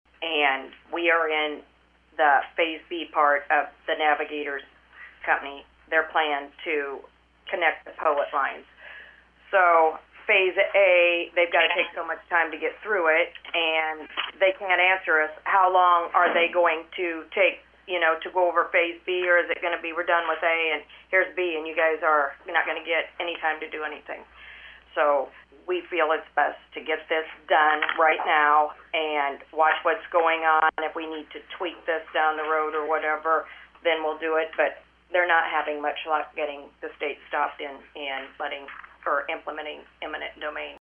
Board member Jodie Hoadley said having an Ordinance regulating where the pipeline is allowed to go, is the best protection the County has right now.